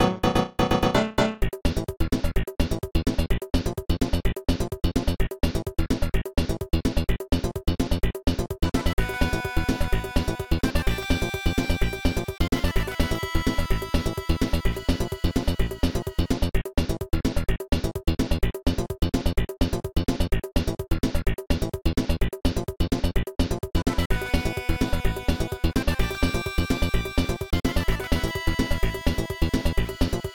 contribs)Added fadeout